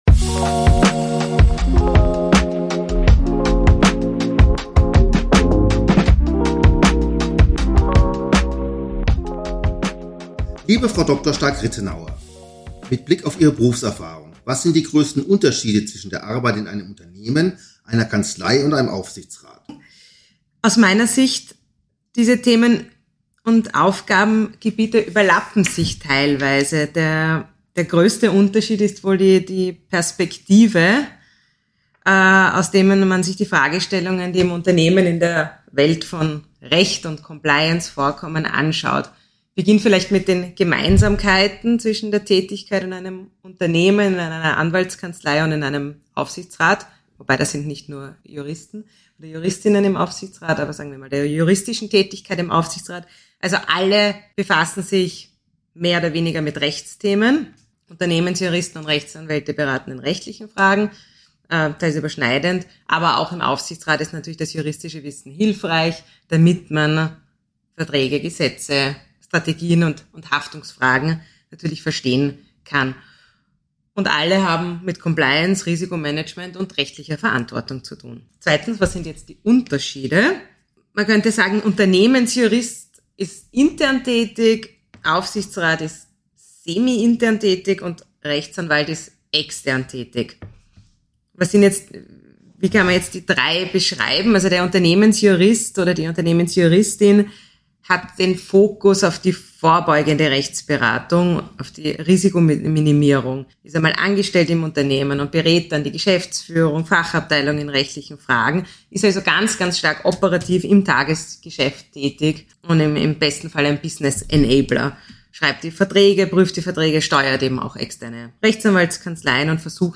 November 2023 #51: Finance: Wie der einfache Zugang zu Kryptowährungen gelingen kann. Interview